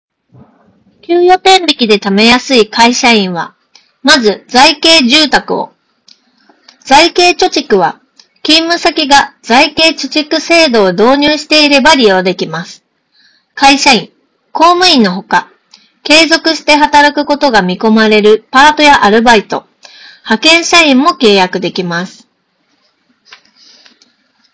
ノイキャン効果は高く、周囲のノイズを効果的に取り除き、装着者の声のみをクリアに拾い上げることができていた。
さすがに専用のハイエンドマイクと比べると録音品質は劣るものの、普通に通話するには十分に優れた性能となっている。
▼SOUNDPEATS Chasersの内蔵マイクで拾った音声単体
周囲の環境ノイズ(空調音や屋外の音)を効果的に除去し、発言内容を明瞭に拾い上げることができていることが分かる。
soundpeats-chasers-earbuds-review.wav